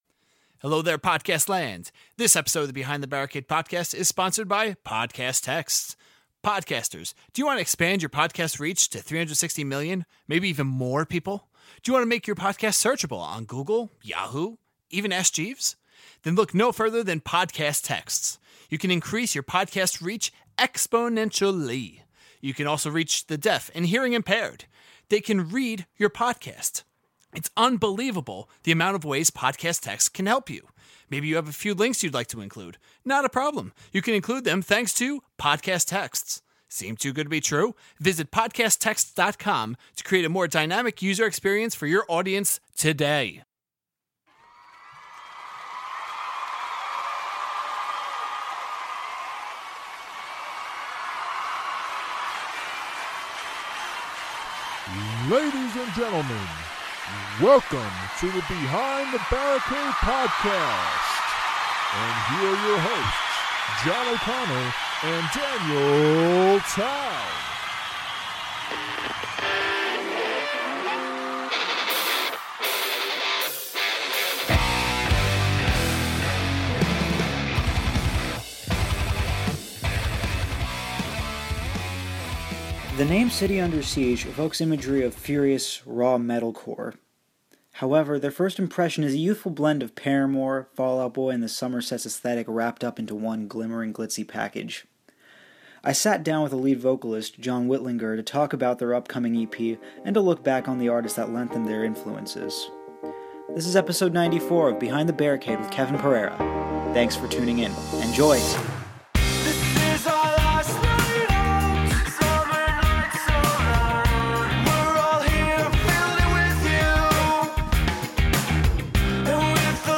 Pre-Interview Song: Shake Post-Interview Song: Killing Time